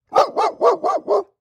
Воу воу во во воу